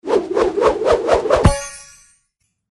CollectModule_Res_万能卡旋转兑换成功.mp3